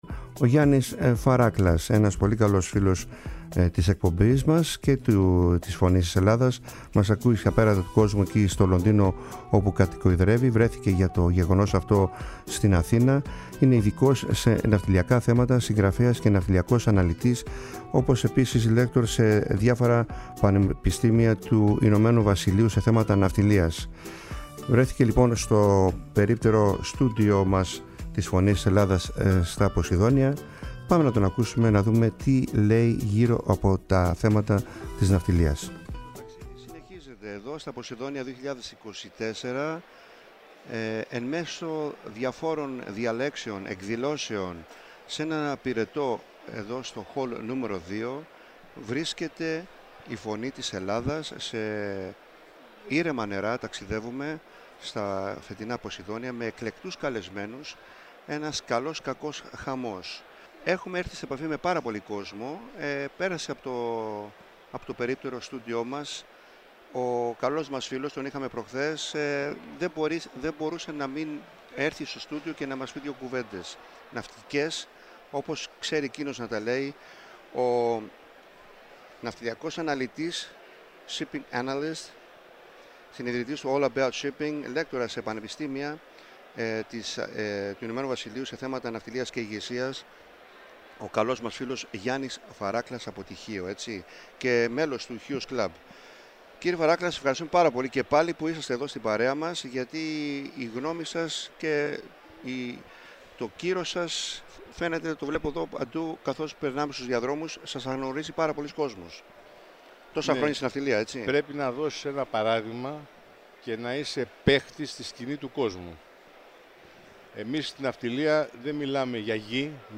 Κατά τη διάρκεια των ΠΟΣΕΙΔΩΝΙΩΝ 2024 επισκέφτηκε το περίπτερο – στούντιο της “Φωνής της Ελλάδας” στην έκθεση και μοιράστηκε σκέψεις και προβληματισμούς γύρω από την Ελληνική Εμπορική Ναυτιλία του σήμερα και του αύριο σε μια πολύ ενδιαφέρουσα συζήτηση-συνέντευξη.
Συνεντεύξεις